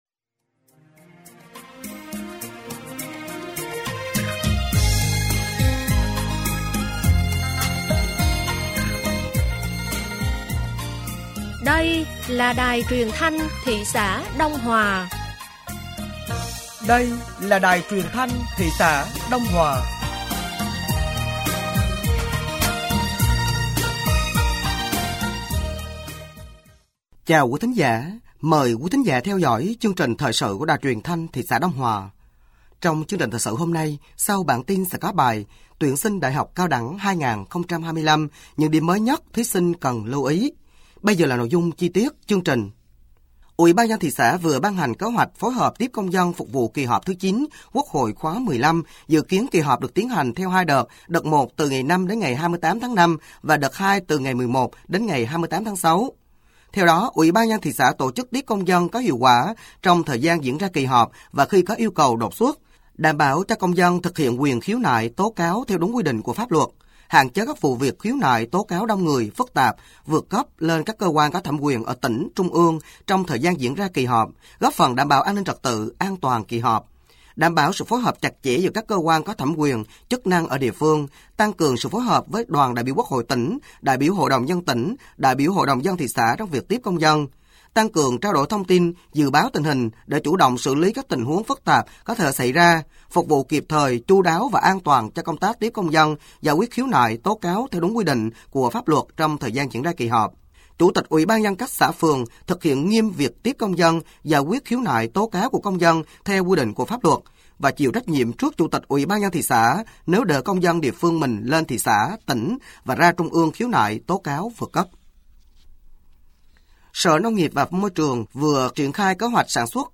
Thời sự tối ngày 25 và sáng ngày 26 tháng 5 năm 2025